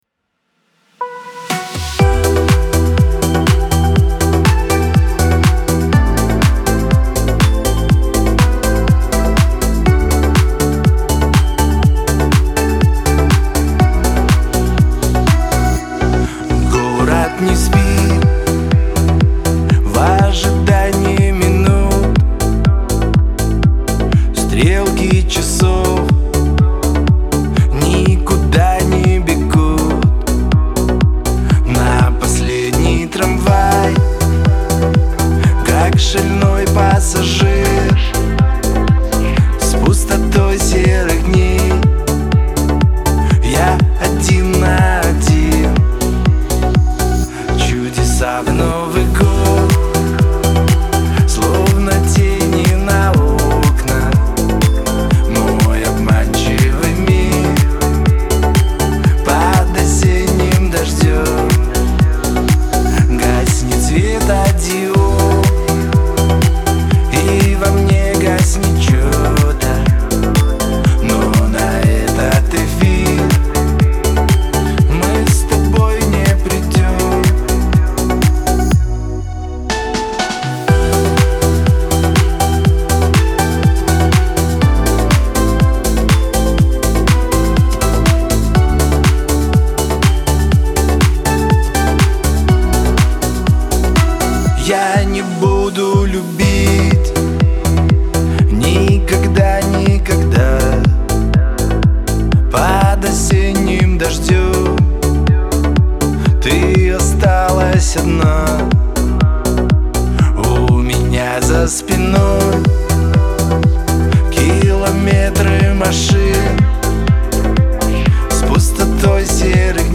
это яркая и ироничная песня в жанре поп-рок